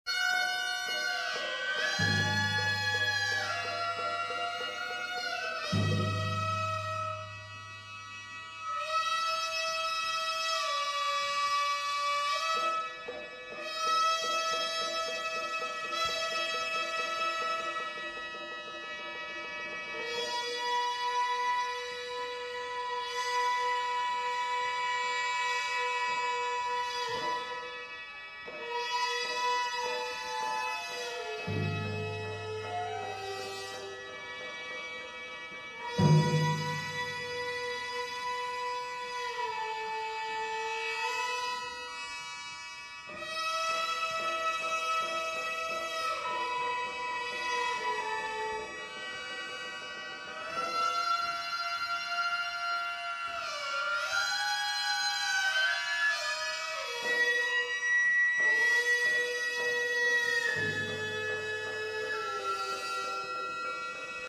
西小タイムから３校時に、体育館で創立記念集会を行いました。
６名の雅楽会の皆さんが、楽太鼓、龍笛、鞨鼓（かっこ）、篳篥（ひちりき）、笙、鉦鼓（しょうこ）などの楽器を使って、
雅楽は、１２００年から１３００年前に生まれた、世界で１番古いオーケストラだそうです。
指揮者のかわりに、女性の方がたたく鞨鼓（かっこ）という小太鼓が合図になっているそうです。
子どもたちは、初めて聞く雅楽の音色に、不思議そうな表情でじっと聴き入っていました。